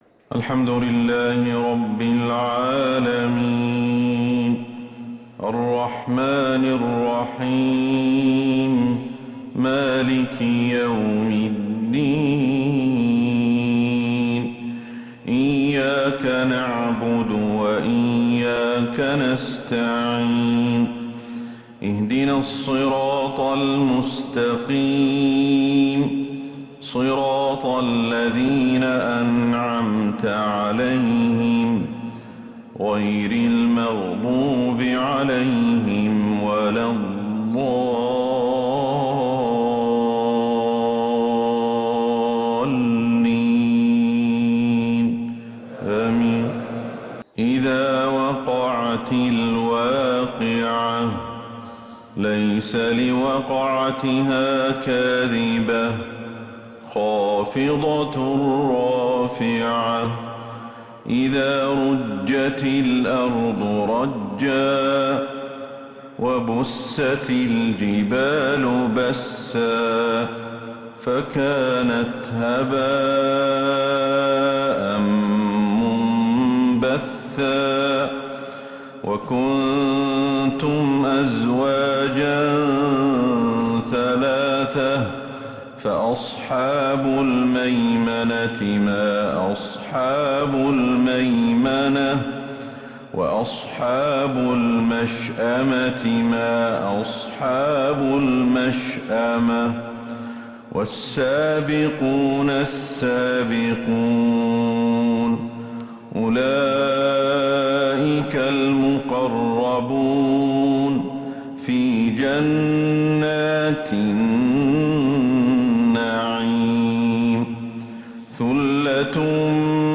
فجر الأربعاء 20 جمادى الأولى 1444هـ فواتح سورة {الواقعة} > 1444هـ > الفروض - تلاوات الشيخ أحمد الحذيفي